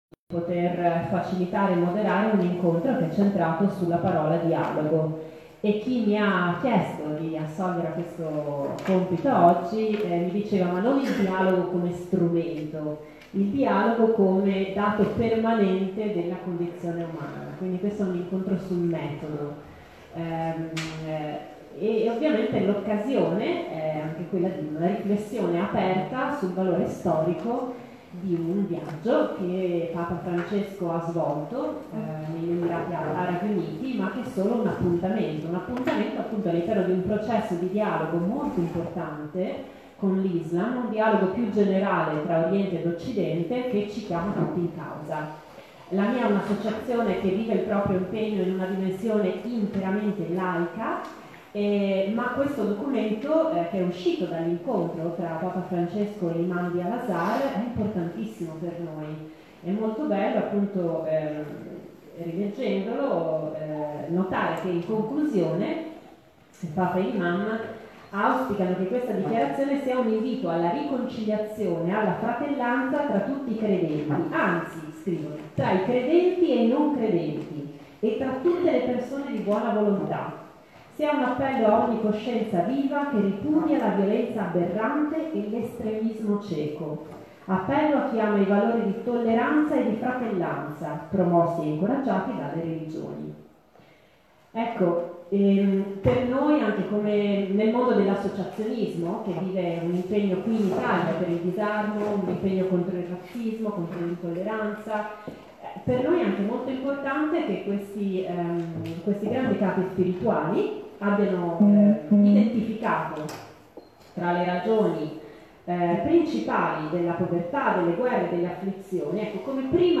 AUDIO DELL’INCONTRO
Le parole pronunciate da Papa Francesco, lo scorso 31 marzo, durante la conferenza stampa al rientro da Rabat, riassumono perfettamente i presupposti dell’incontro organizzato dalla nostra Fondazione, lo scorso 6 aprile, presso la Biblioteca dei Cappuccini.